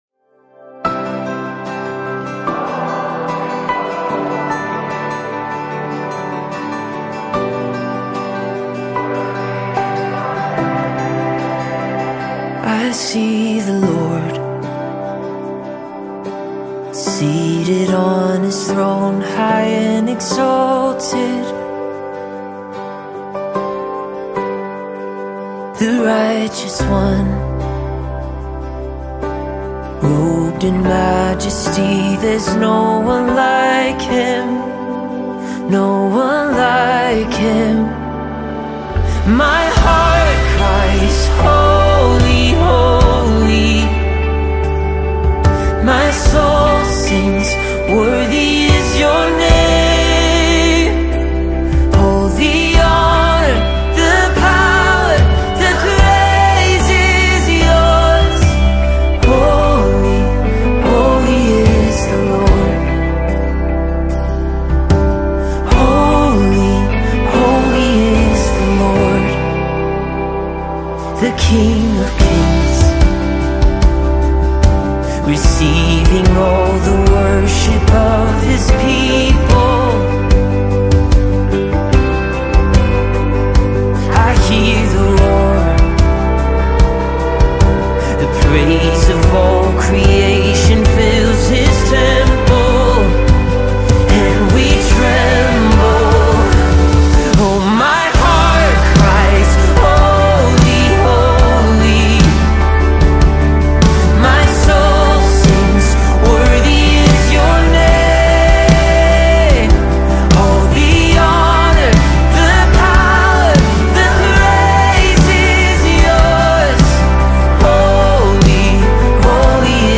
Seattle Worship band